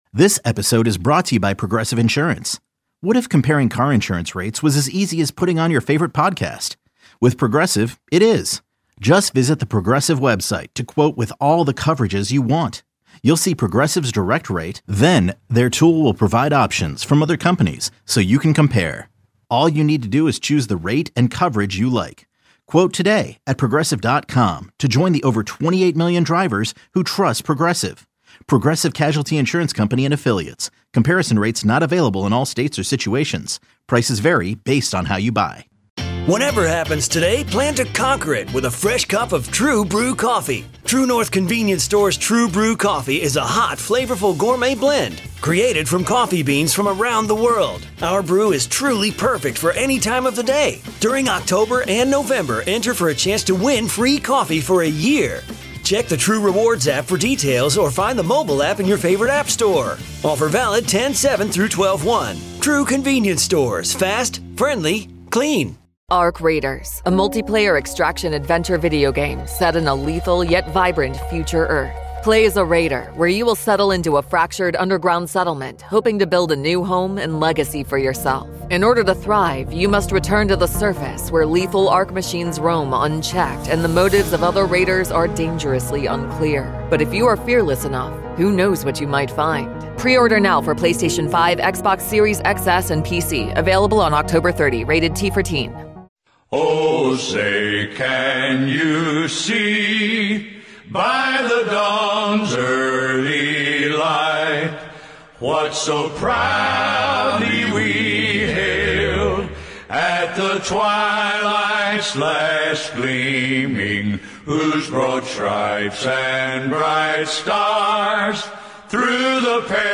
National Anthem sung by Oak Ridge Boys.